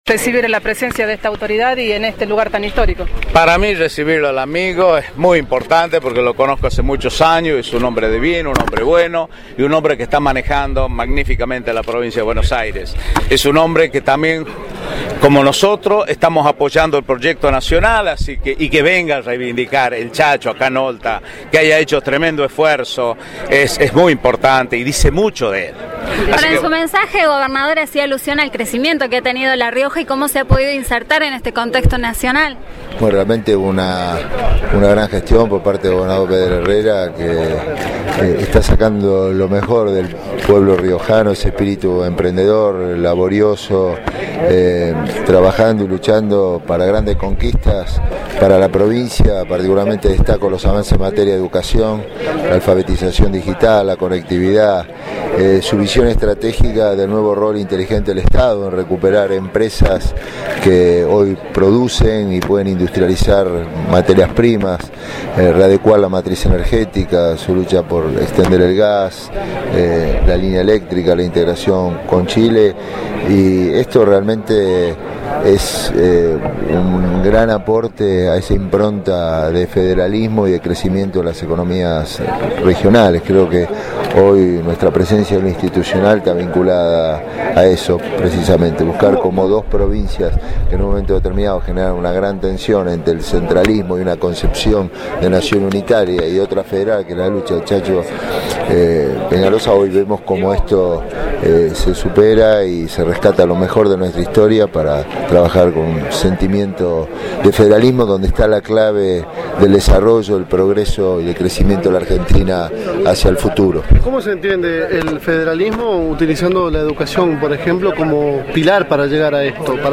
El gobernador de Buenos Aires, Daniel Scioli, en su mensaje al participar del acto central por el 149 aniversario del asesinato de Ángel Vicente Peñaloza, consideró que si la Provincia sigue por este camino, le dará muchas oportunidades a los riojanos.